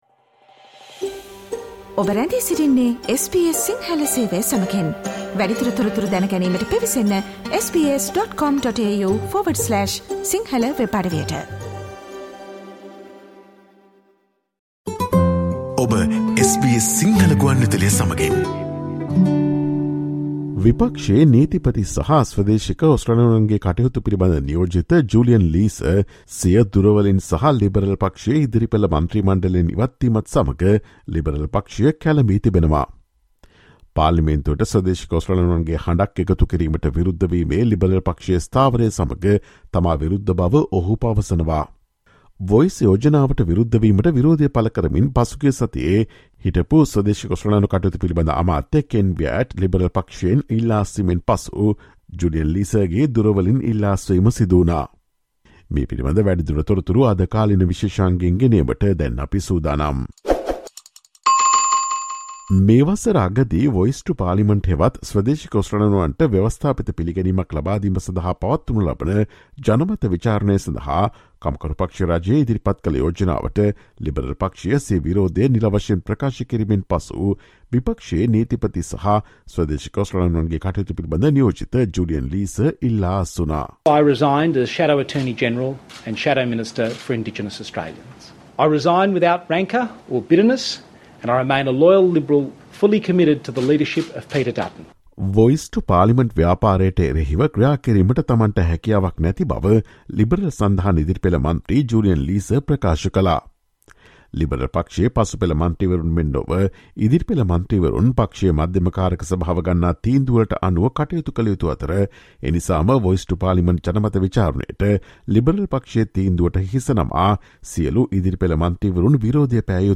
The Liberal Party is in turmoil, as its spokesman for Indigenous Australians quits the opposition front bench. Julian Leeser - who is also Shadow Attorney-General, says he's at odds with the party's position to oppose the Indigenous Voice to Parliament. Listen to the SBS Sinhala Radio's current affairs feature on Thursday, 13 April 2023.